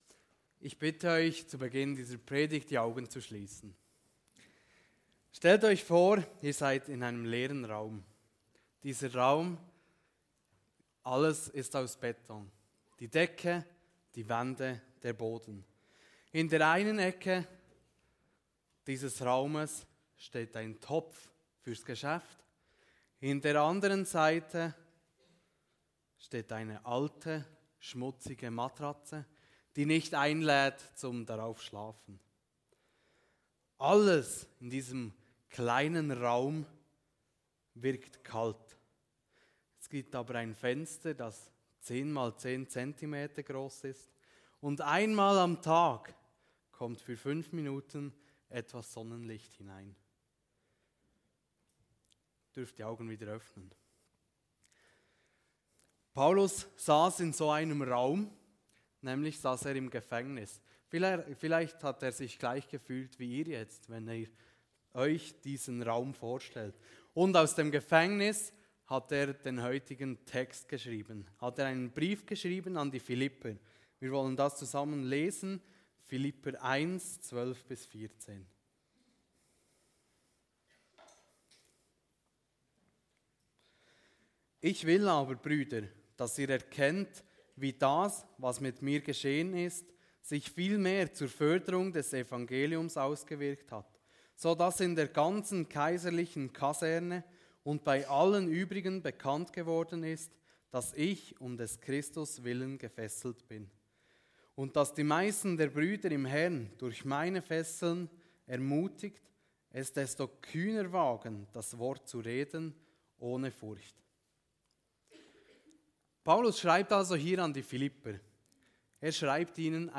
Kategorie: Predigt